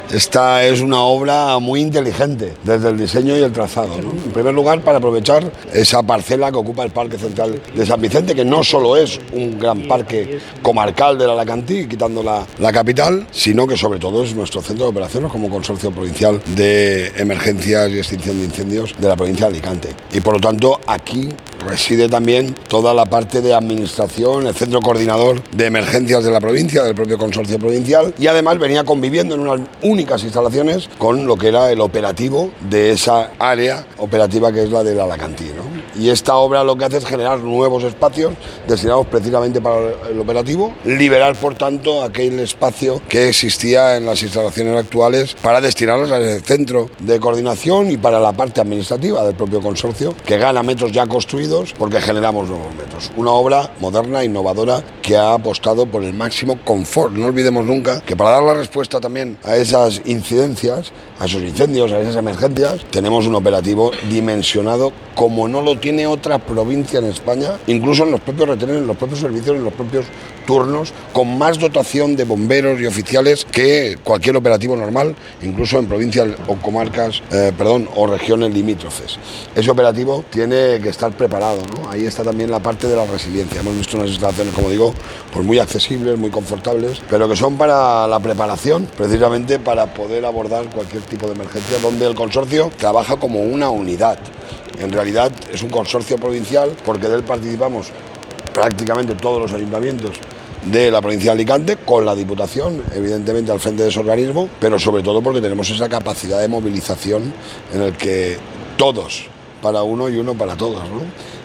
Parque-Bomberos-Sant-Vicent-del-Raspeig-CORTE-Toni-Perez.mp3